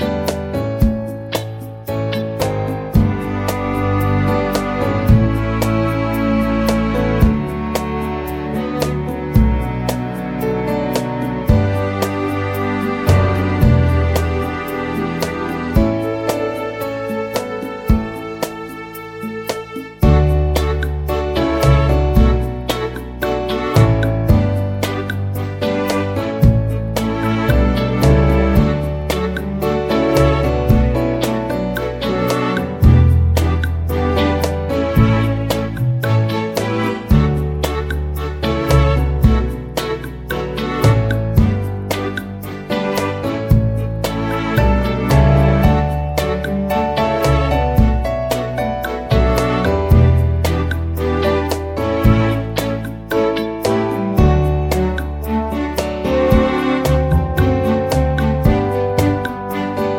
Minus Main Guitars Pop (1990s) 3:46 Buy £1.50